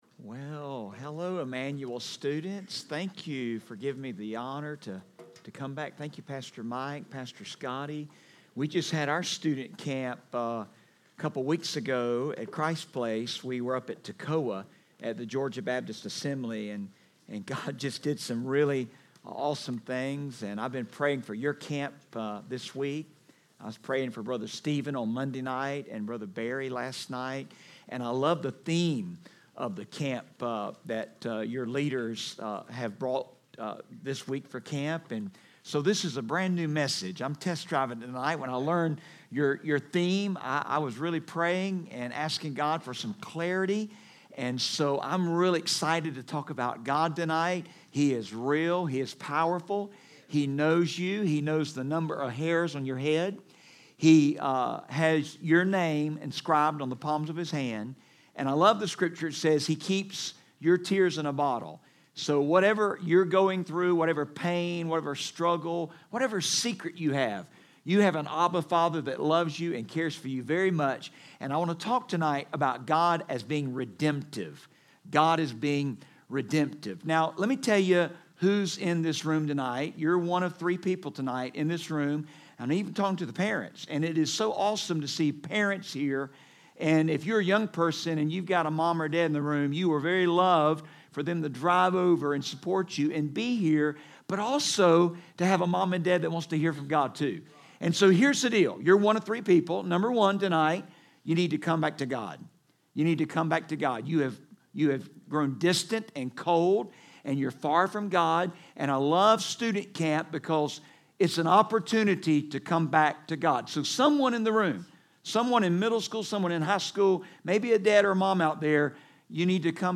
From the evening session at ESM Summer Camp on Wednesday, June 26, 2019